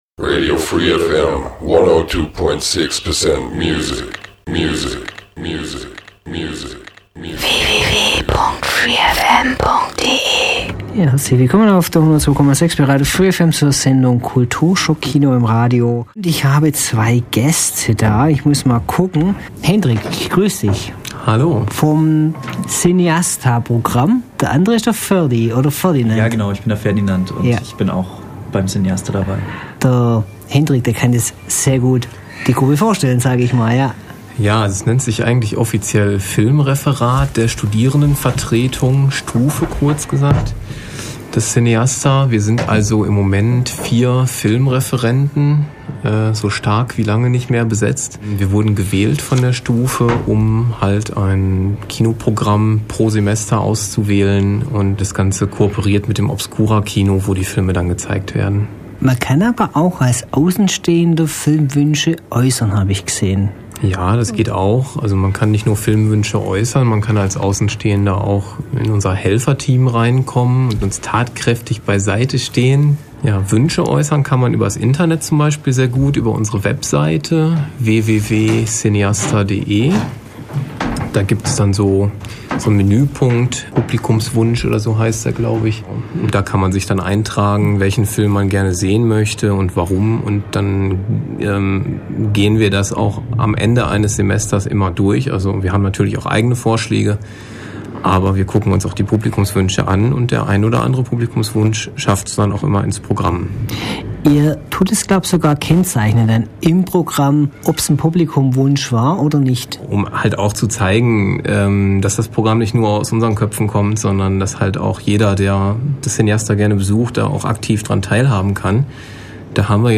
Kulturschock mit Interview zu Cineasta